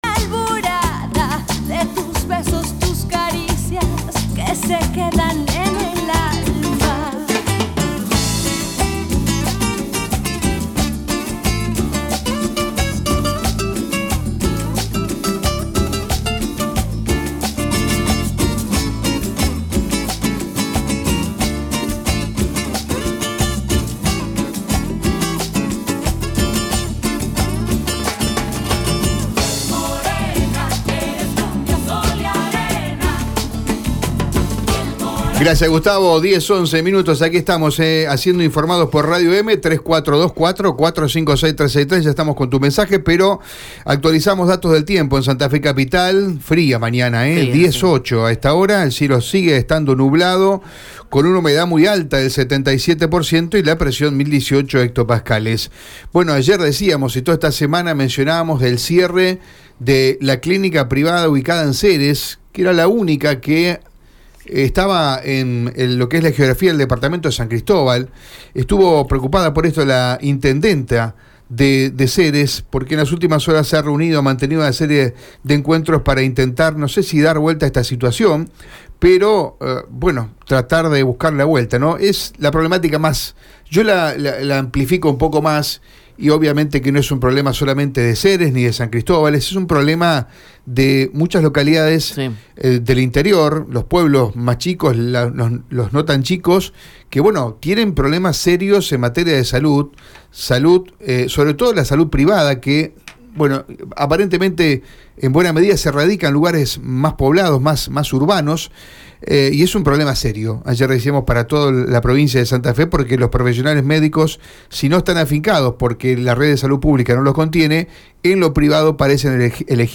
La jefa del Municipio de Ceres, Alejandra Dopouy analizó la noticia en Radio EME .
INT-DE-CERES-ALEJANDRA-DUPUY.mp3